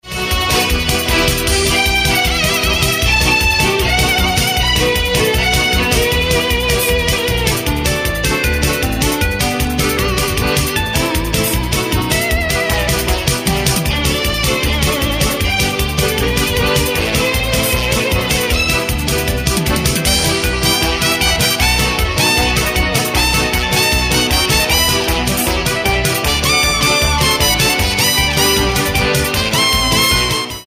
• Жанр: Кантри